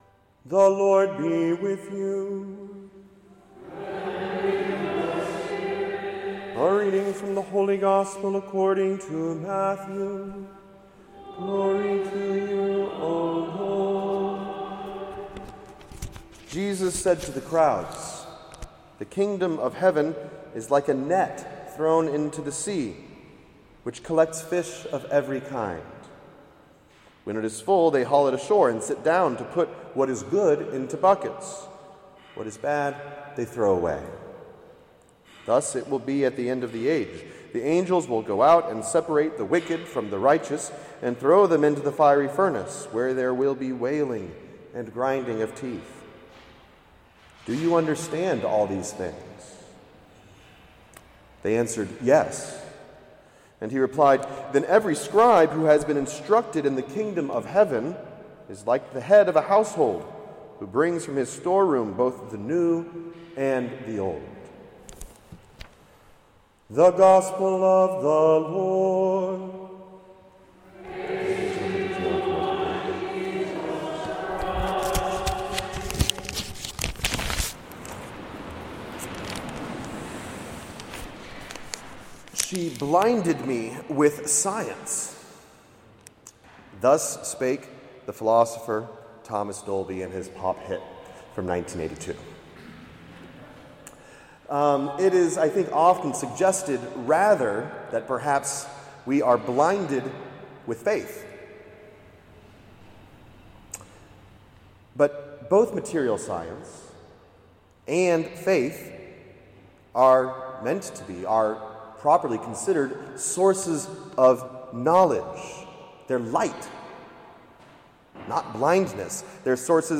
2025 Annual Gold Mass for Catholic Scientists - Homily
On Saturday, November 15, 2025, Collegium Institute hosted a Gold Mass for Catholic Scientists at St. Agatha-St. James Church. Gold Masses are celebrated for Catholics who are or have been involved with the sciences.